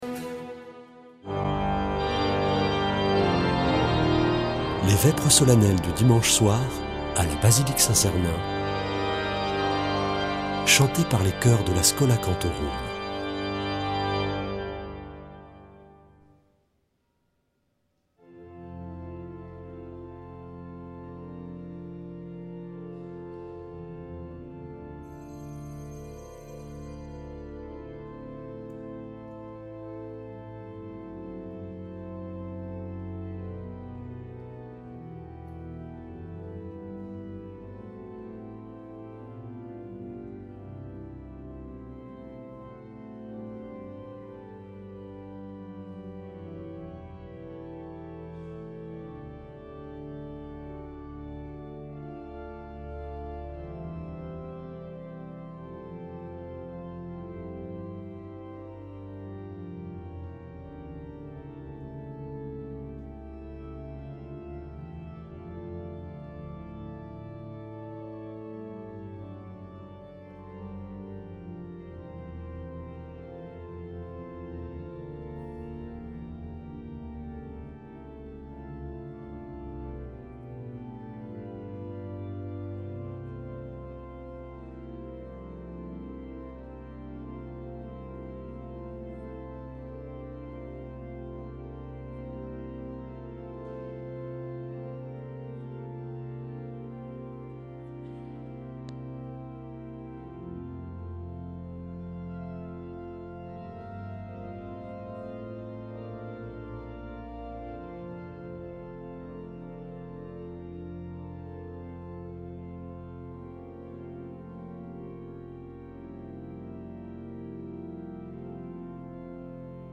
Vêpres de Saint Sernin du 10 nov.
Schola Saint Sernin Chanteurs